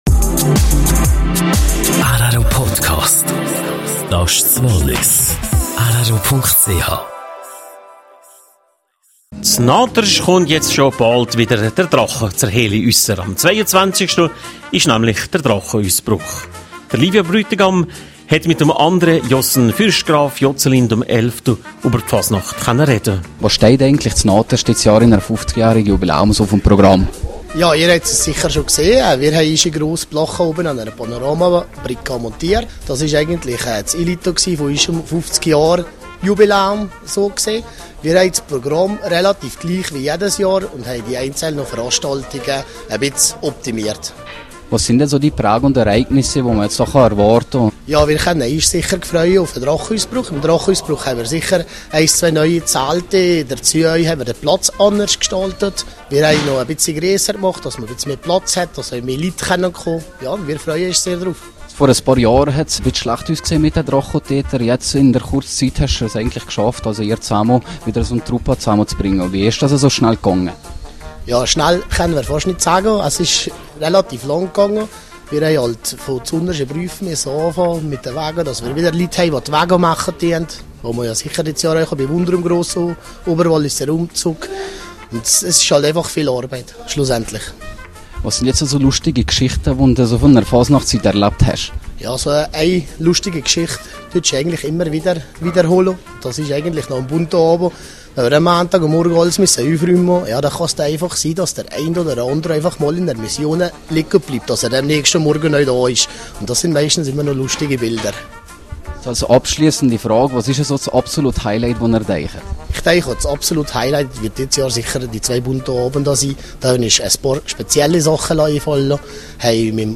Im Interview mit drei Mitgliedern der Drachentöter Naters.